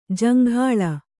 ♪ jaŋghāḷa